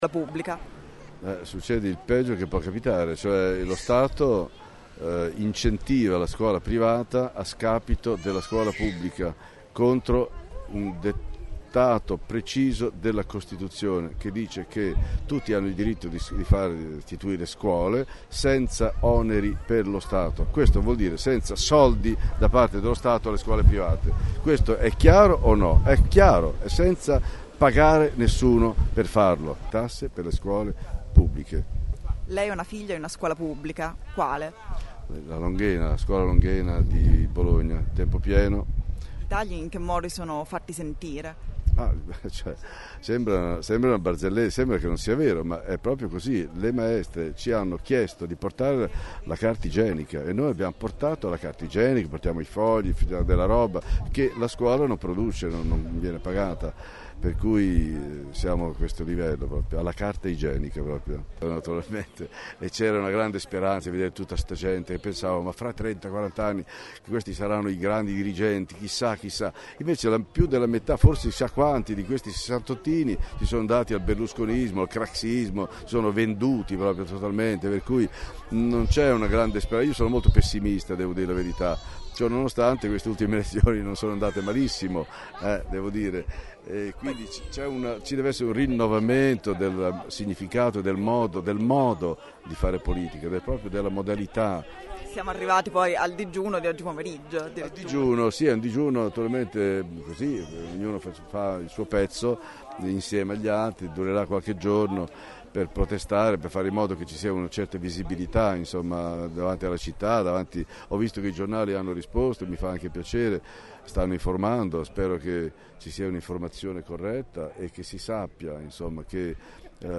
Tra i manifestanti anche l’attore Ivano Marescotti, in qualità di padre (ascolta
marescotti), che ha letto l’art. 33 della Costituzione e un brano di Piero Calamandrei.